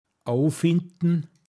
Wortlisten - Pinzgauer Mundart Lexikon
abfinden åofindn